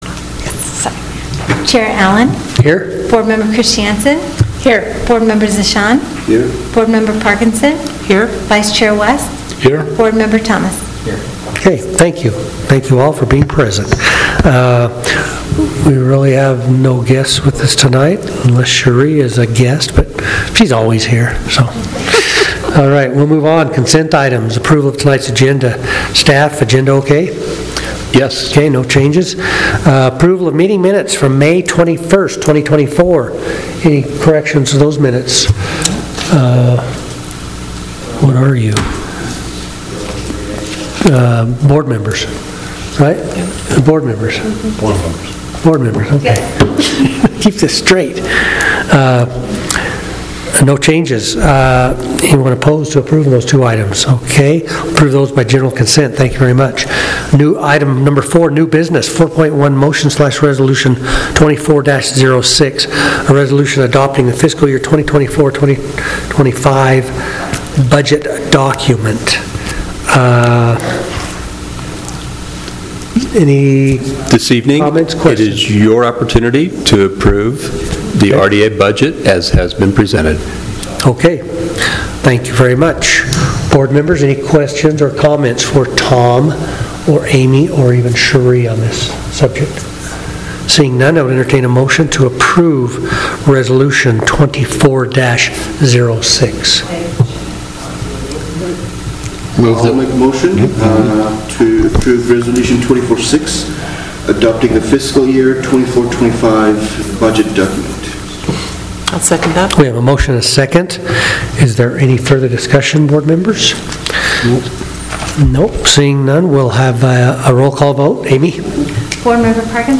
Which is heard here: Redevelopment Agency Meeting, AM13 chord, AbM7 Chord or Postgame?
Redevelopment Agency Meeting